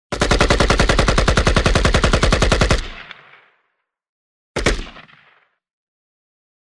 Звуки автомата Калашникова
Звук выстрелов из автомата Калашникова